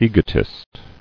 [e·go·tist]